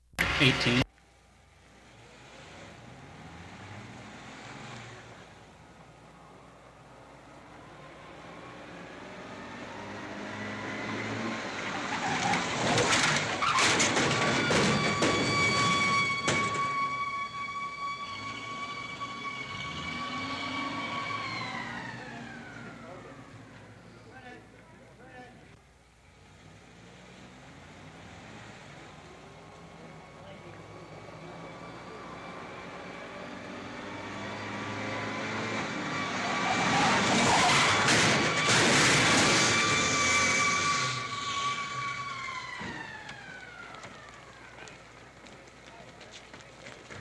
古老的碰撞 " G2618鸣笛的汽车碰撞事件
描述：汽车在后台静静地转动，并加速撞到前景中的金属垃圾桶。警报器和声音说话。 2次。在舞台上。 这些是20世纪30年代和20世纪30年代原始硝酸盐光学好莱坞声音效果的高质量副本。 40年代，在20世纪70年代早期转移到全轨磁带。我已将它们数字化以便保存，但它们尚未恢复并且有一些噪音。